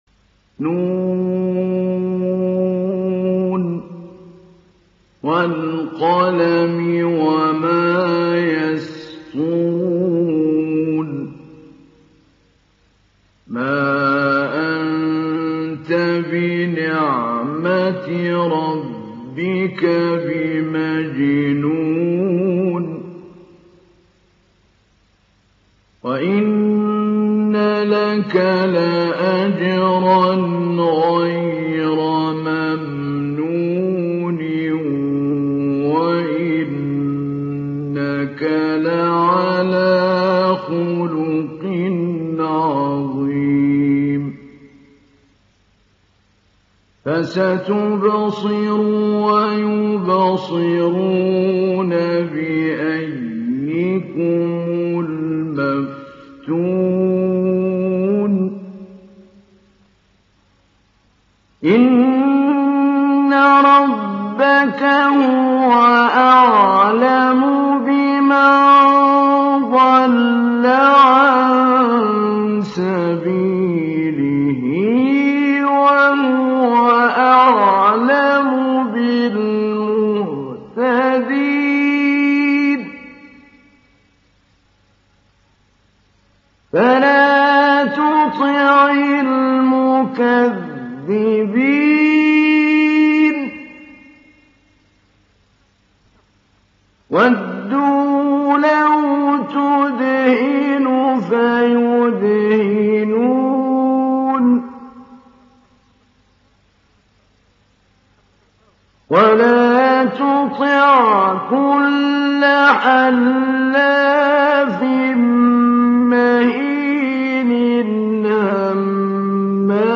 ডাউনলোড সূরা আল-ক্বালাম Mahmoud Ali Albanna Mujawwad